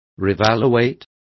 Complete with pronunciation of the translation of revaluating.